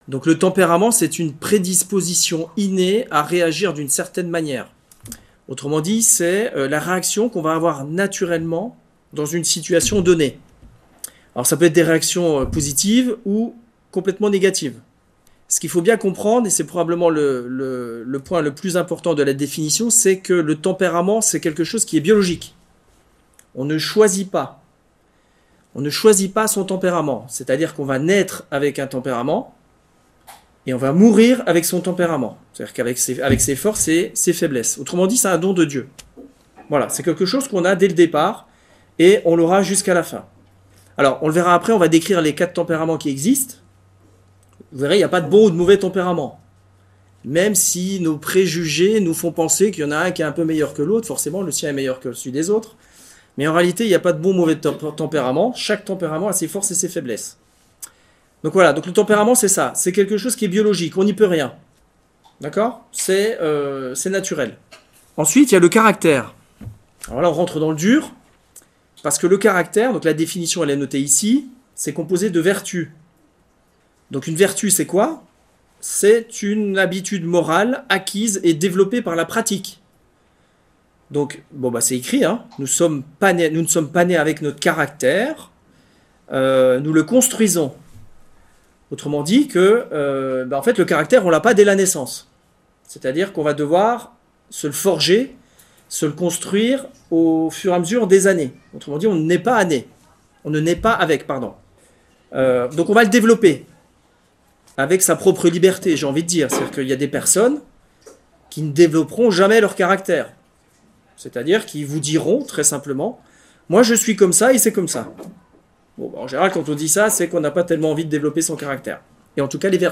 ND du Laus. Festival Marial 2024